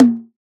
edm-perc-32.wav